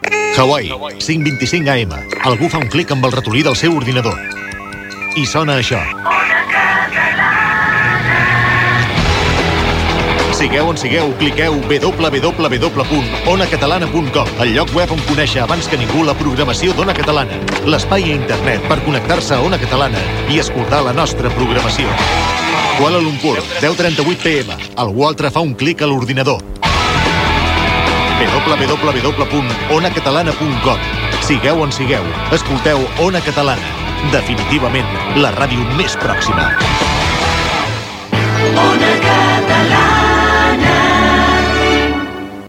Promoció de la pàgina web de l'emissora a Internet, indicatiu de l'emissora.
FM